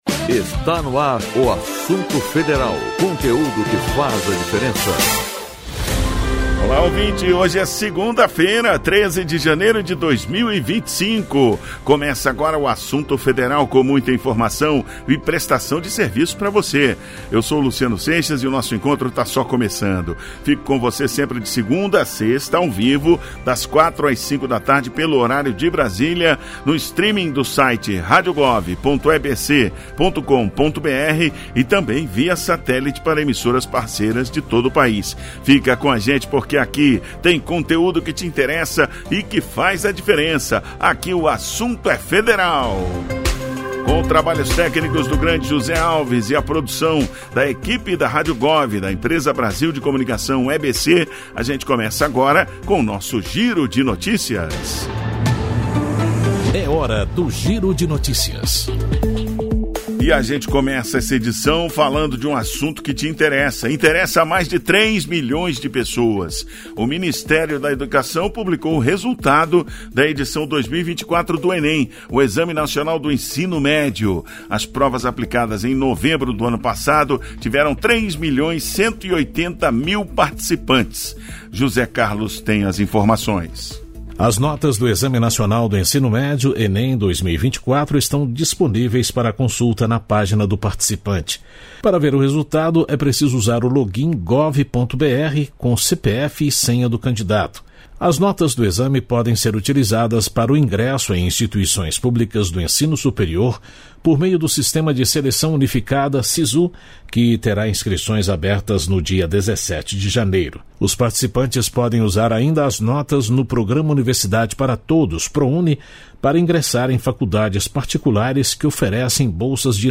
Edição desta segunda-feira (23) traz um especial para a semana de natal com entrevista , me conta Brasil , música e muito mais.